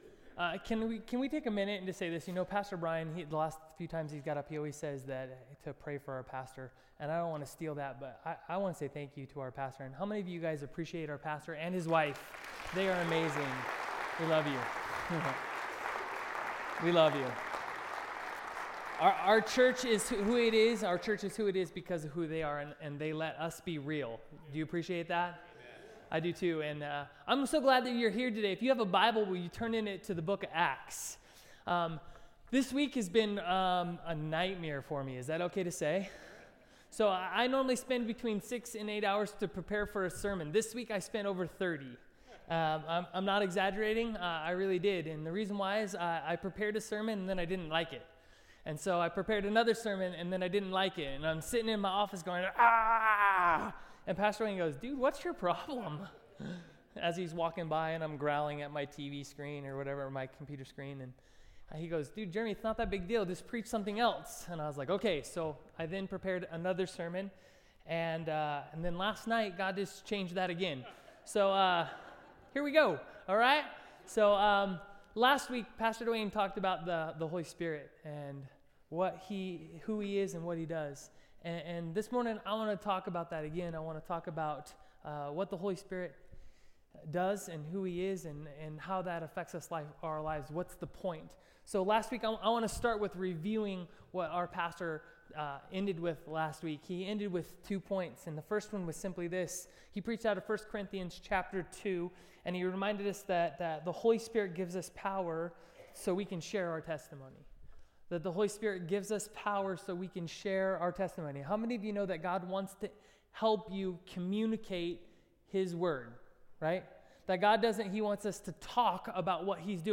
Sermons - Redmond Assembly of God